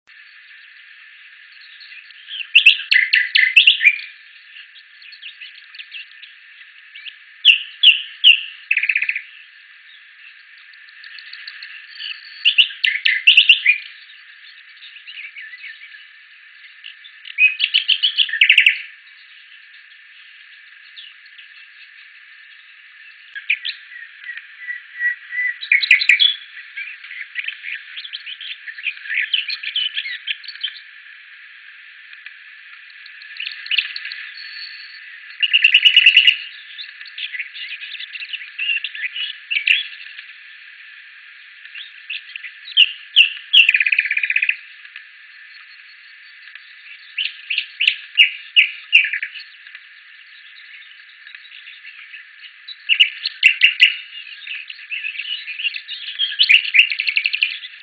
Sydlig Nattergal  Luscinia megarhynchos  DK listen
Lokalitet:Vangen Ø for Nykøbing
båndoptagelse af sangen, så vi håber meget at SU denne gang lader os få arten på plads på Rørviglisten.